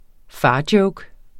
Udtale [ ˈfɑːˌdjɔwg ]